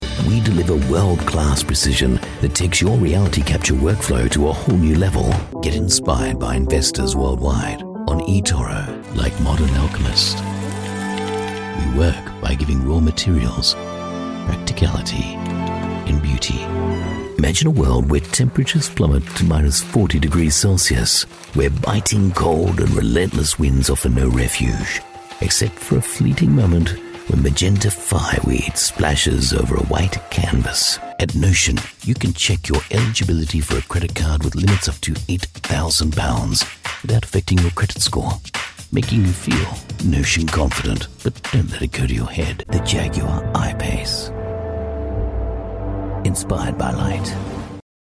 Commercial Demo
Neumann TLM 103 mic